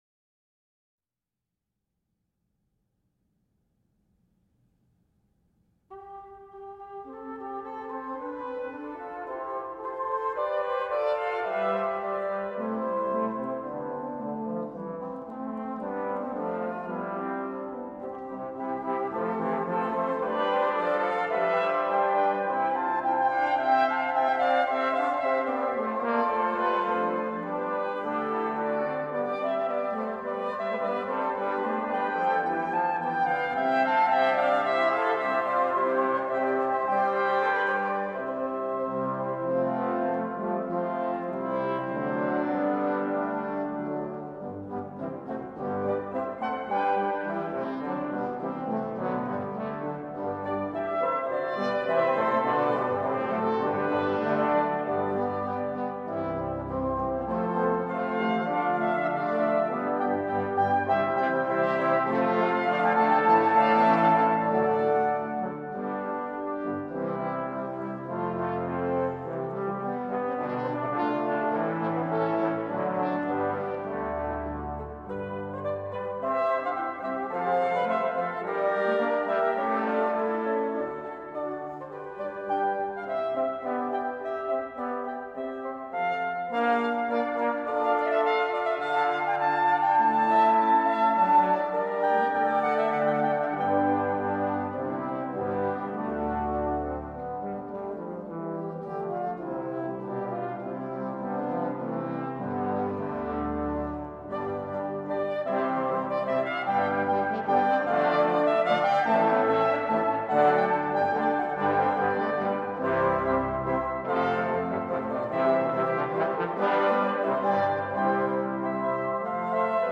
Canzona
Group: Instrumental
It differed from the similar forms of ricercare and fantasia in its livelier, markedly rhythmic material and separation into distinct sections.